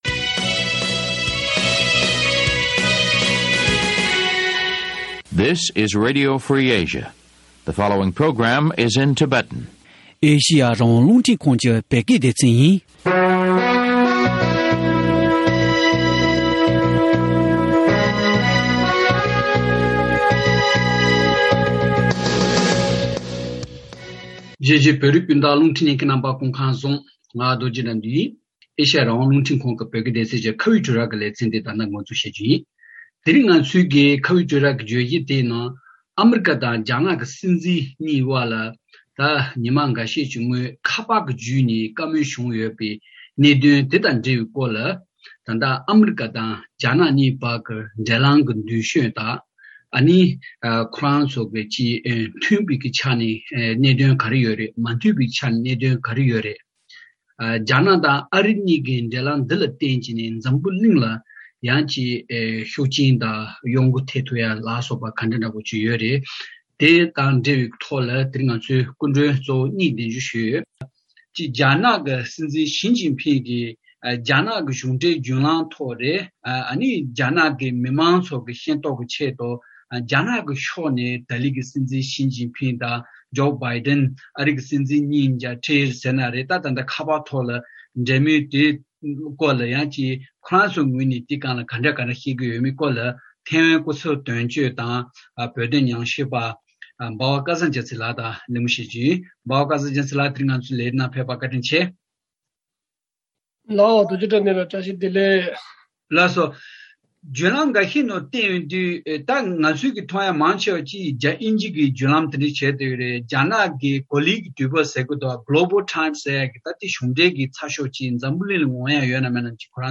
ཨ་རྒྱ་གཉིས་ཀྱི་འབྲེལ་ལམ་གྱི་ཁ་ཕྱོགས་དང་དེའི་ཤུགས་རྐྱེན་ཐད་གླེང་མོལ་ཞུས་པ།